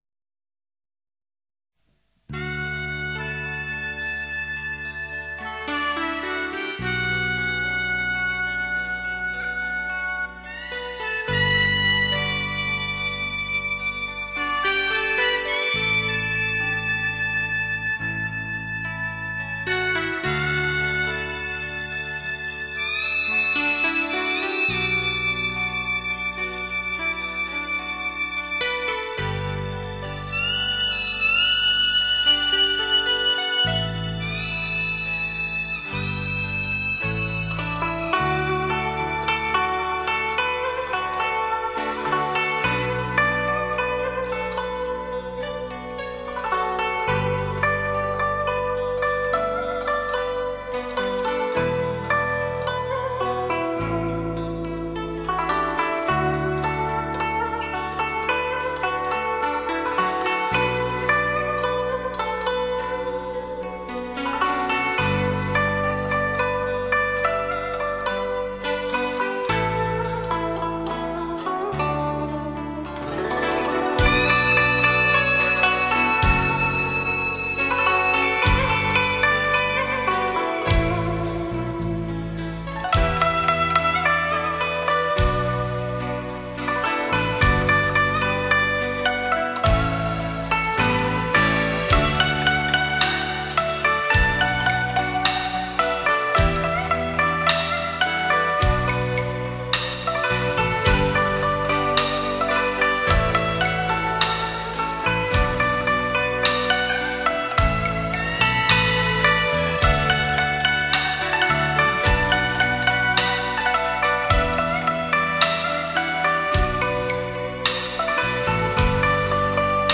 01.宝贝.对不起    (二胡)
02.青青河边草    (琵琶)
05.深秋的黎明    (古筝)